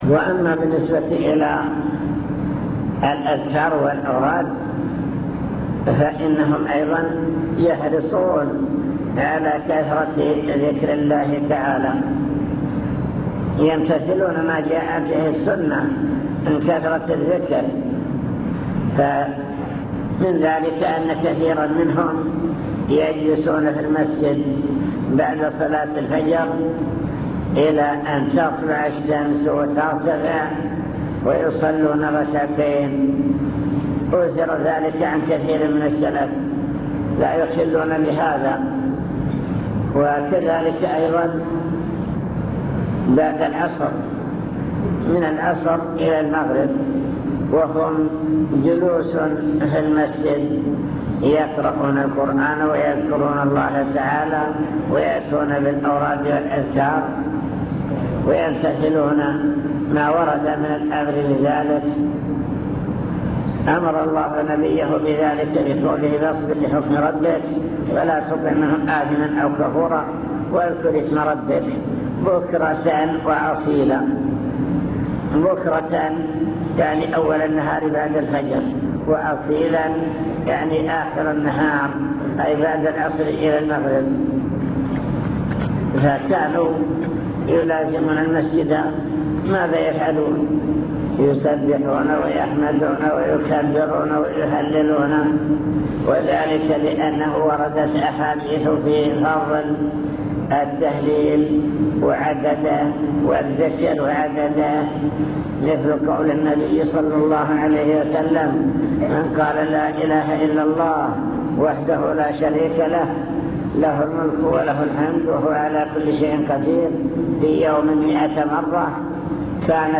المكتبة الصوتية  تسجيلات - محاضرات ودروس  محاضرة في النصرية أحوال سلف الأمة في العبادة